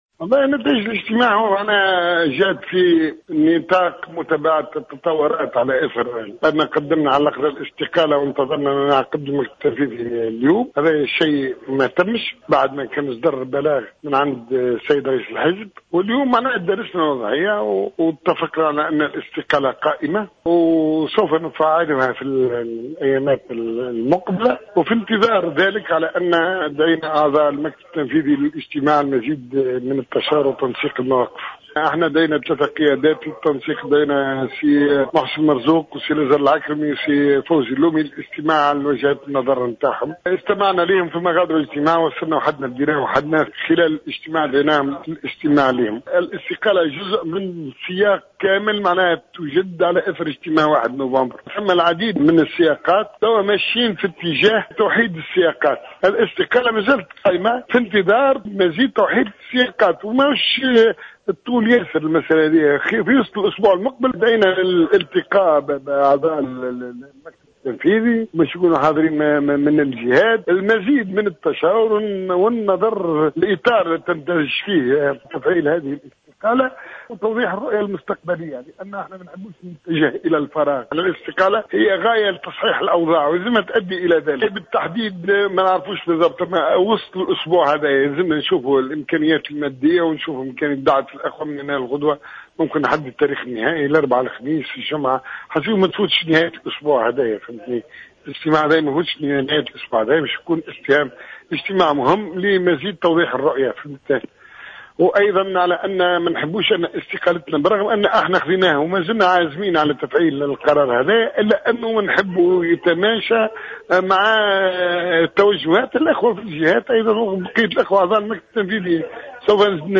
قال النائب المستقيل من كتلة "نداء تونس" مصطفى بن أحمد أن استقالة مجموعة الـ 31 نائبا لا تزال قائمة وسيتم تفعيلها خلال أيام، في تصريحات اليوم الأحد لـ "الجوهرة أف أم".